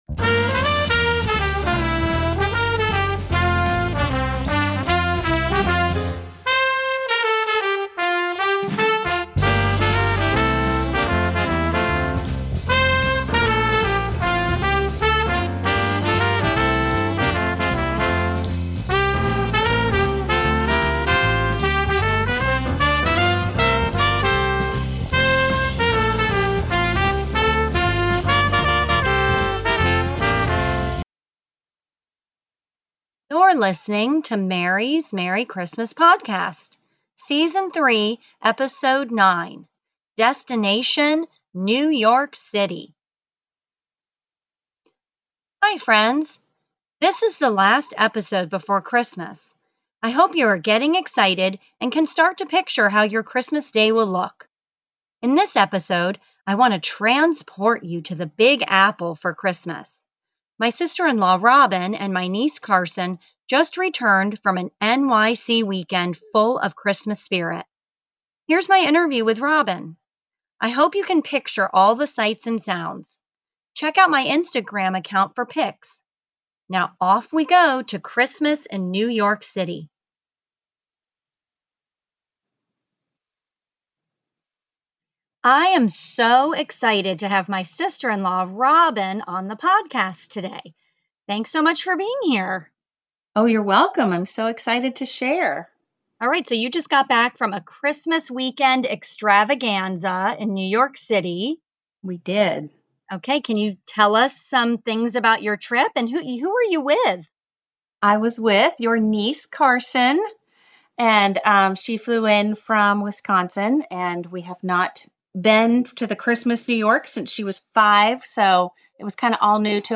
In this extra special interview episode